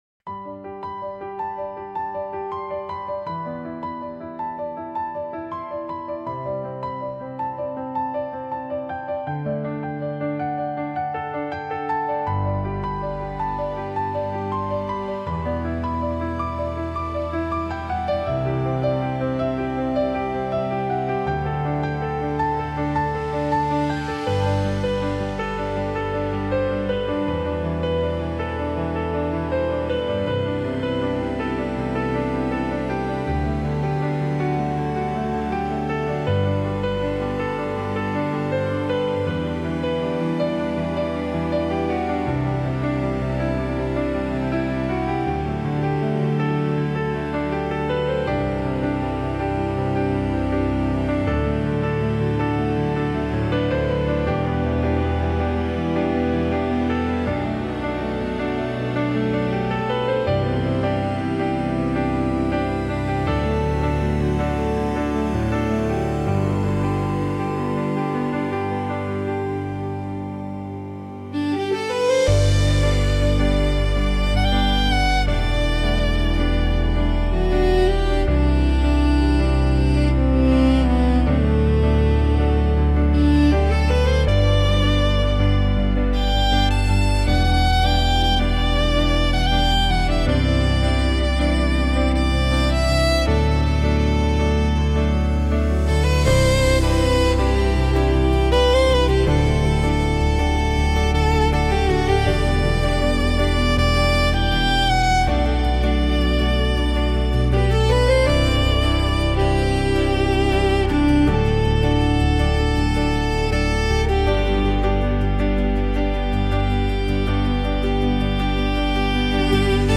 著作権フリーオリジナルBGMです。
シネマティック・映画音楽風・感動系・ボーカル無し